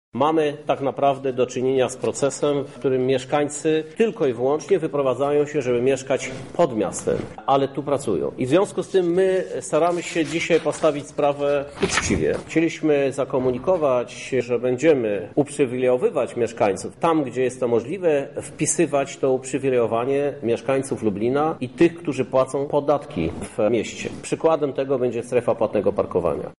Modyfikacje są spowodowane tym, że do kasy miasta w ramach rozliczenia podatku trafi 90 mln złotych mniej – tłumaczy prezydent miasta Krzysztof Żuk.